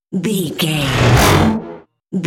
Whoosh electronic fast
Sound Effects
Atonal
Fast
futuristic
high tech
whoosh